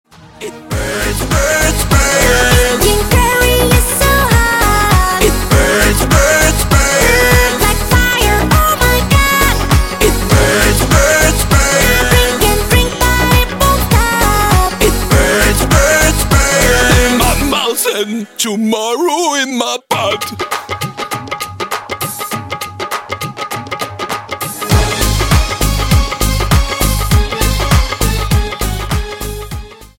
• Качество: 128, Stereo
поп
мужской голос
заводные
танцевальные
электронный голос